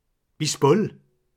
La ville d’origine des auteurs (versions écrites) et des locuteurs (versions orales) est précisée en italique.
Masevaux